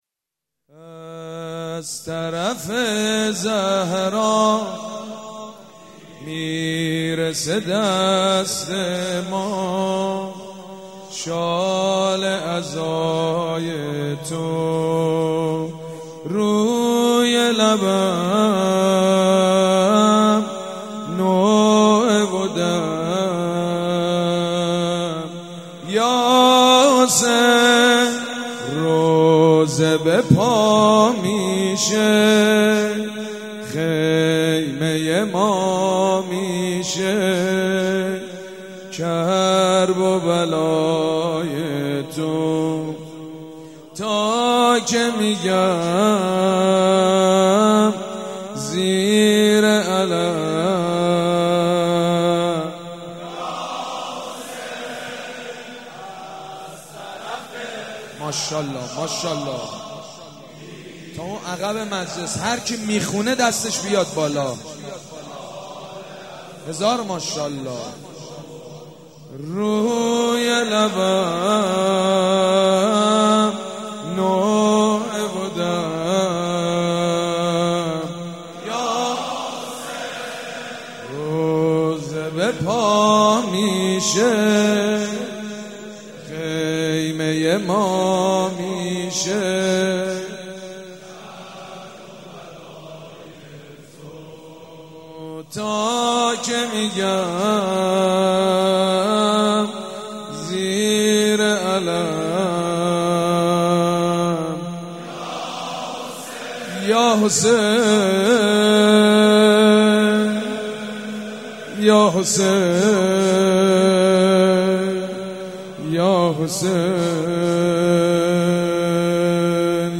صوت: مجیدبنی فاطمه- شب پنجم محرم
صوت مراسم شب پنجم محرم ۱۴۳۷هیئت ریحانه الحسین(ع) ذیلاً می‌آید: